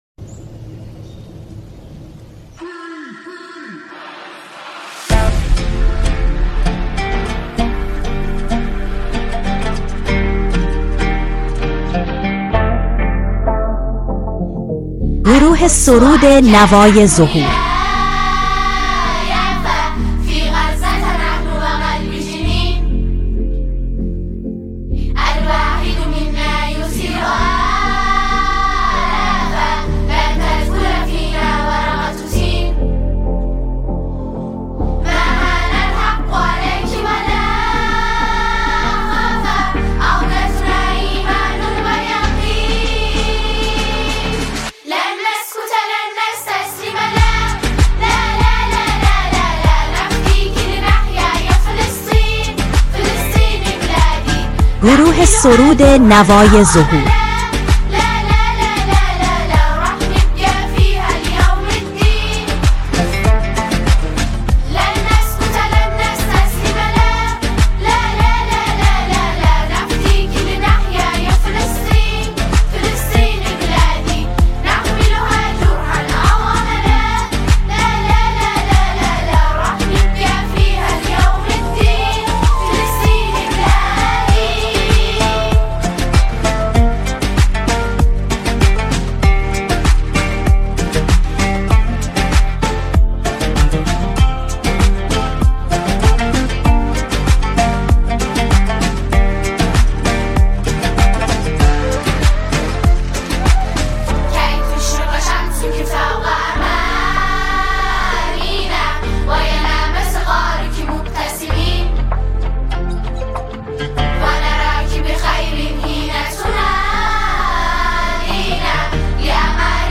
سرود حماسی و عربی
ژانر: سرود